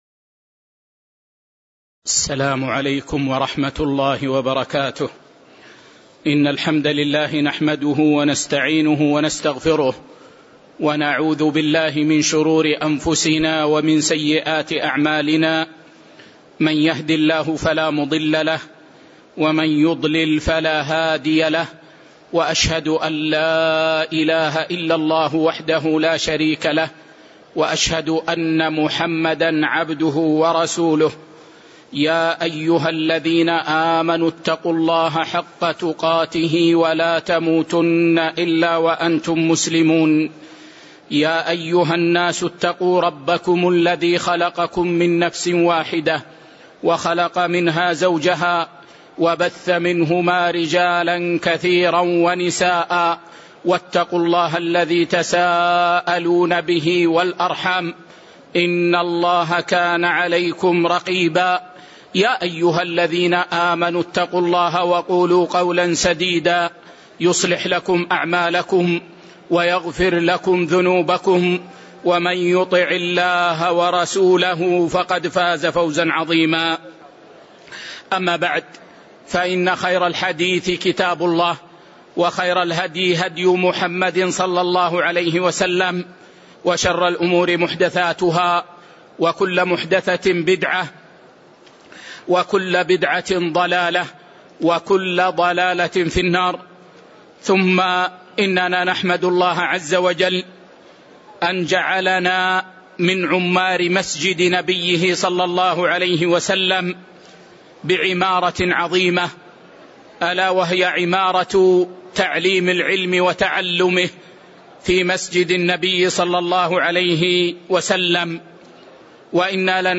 تاريخ النشر ٤ صفر ١٤٤١ هـ المكان: المسجد النبوي الشيخ